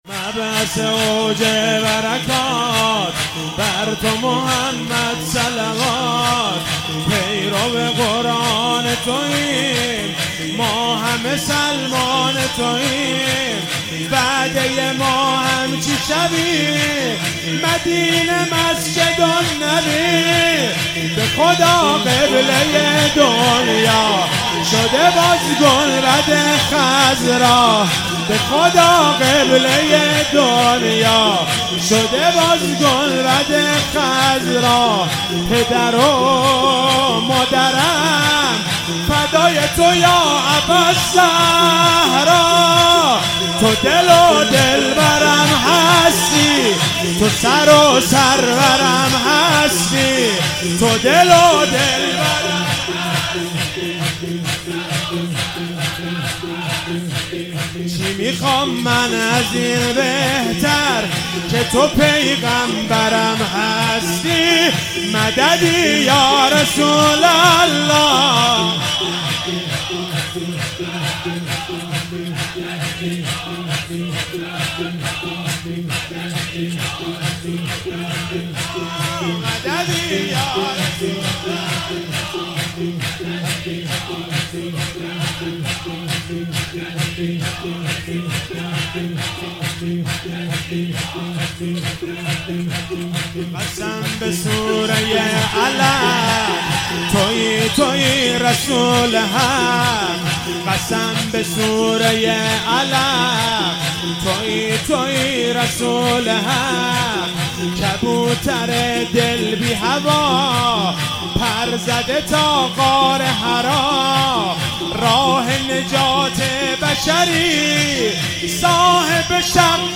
مولودی